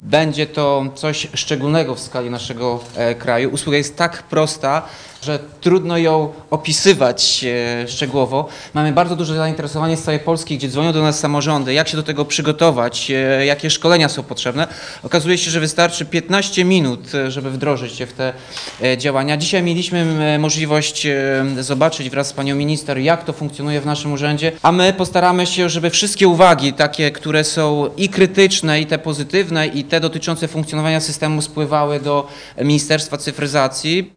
Mieszkańcy oraz ełcki ratusz będą testować usługę do czerwca. Później program rozszerzony zostanie na teren całego kraju, mówi Tomasz Andrukiewicz – prezydent Ełku.
Andrukiewicz-mdokumenty-inauguracja.mp3